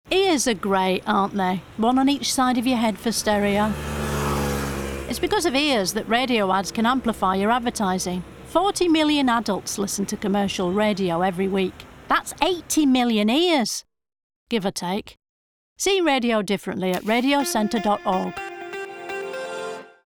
Audio ads, which are voiced by comedian Diane Morgan, were created by Radioville.
From shouting dinner orders abroad to yelling at footballers from the stands, the mix of 30, 20 and 10 second ads comically show how turning up the volume gets you heard – just like great radio advertising.